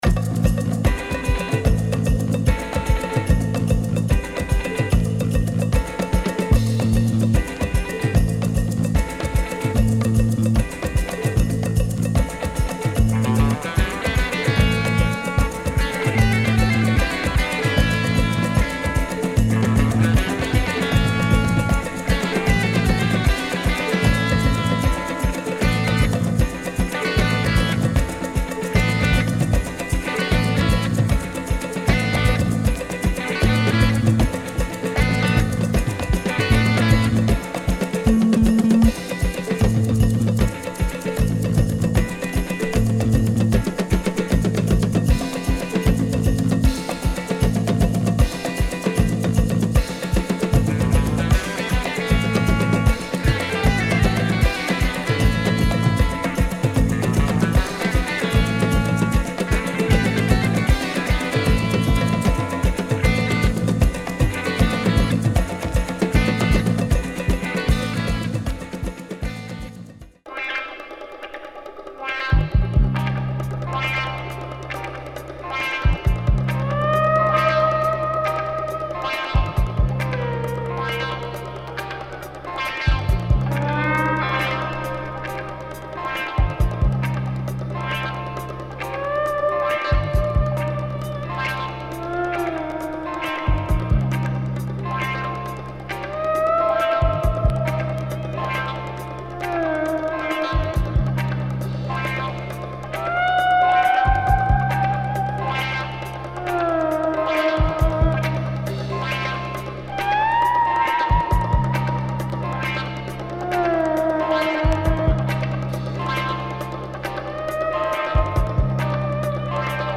Some groovy tunes, some having good breaks